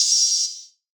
DDK1 OPEN HAT 7.wav